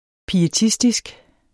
Udtale [ piəˈtisdisg ]